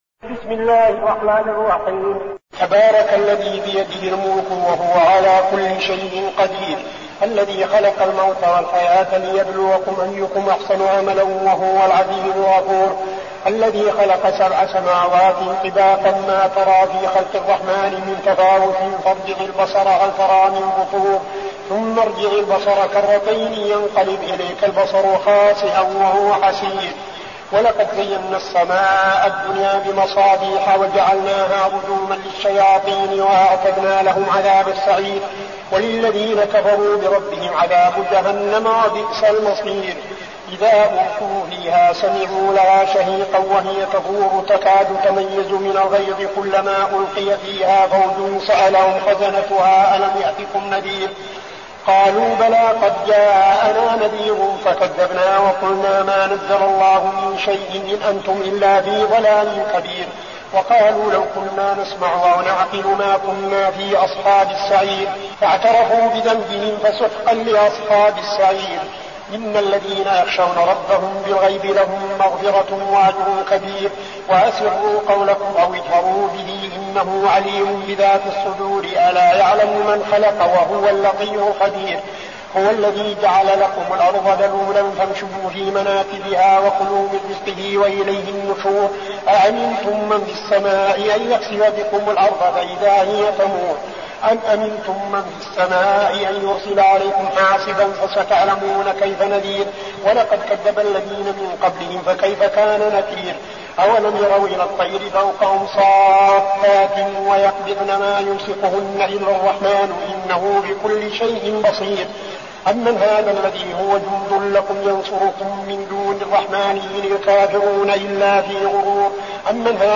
المكان: المسجد النبوي الشيخ: فضيلة الشيخ عبدالعزيز بن صالح فضيلة الشيخ عبدالعزيز بن صالح الملك